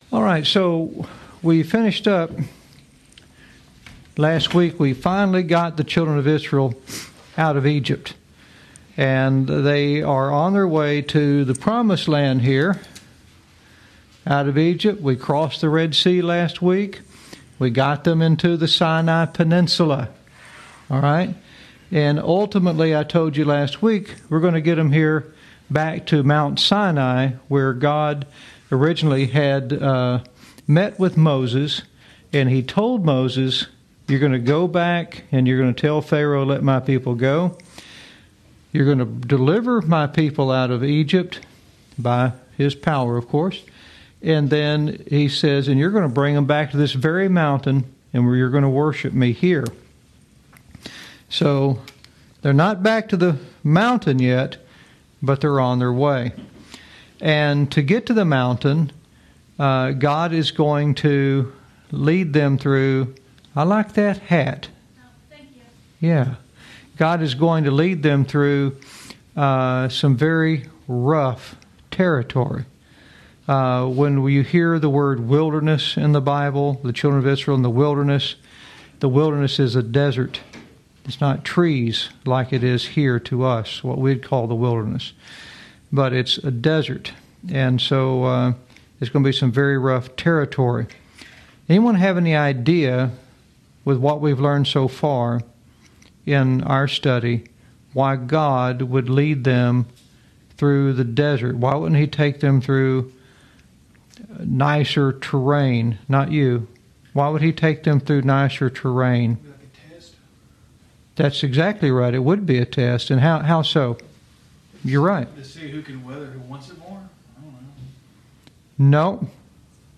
Lesson 21